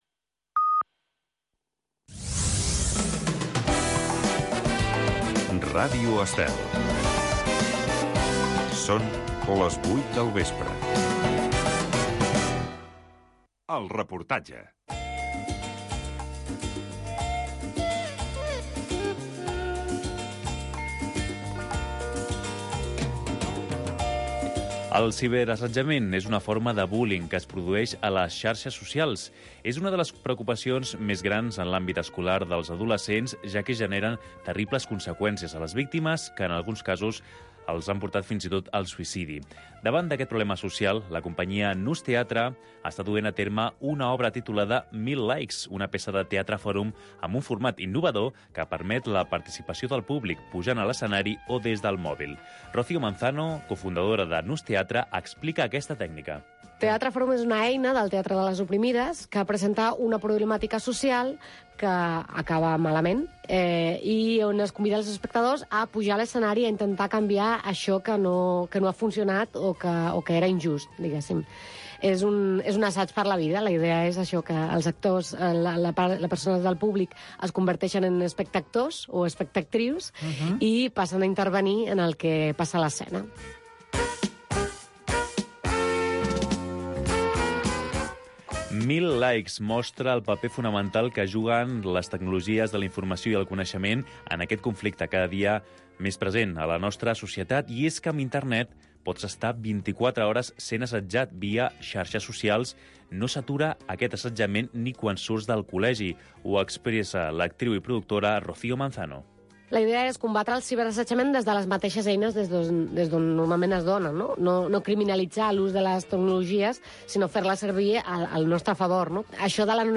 Un programa amb entrevistes i tertúlia sobre la família amb clau de valors humans, produït pel l'associació FERT.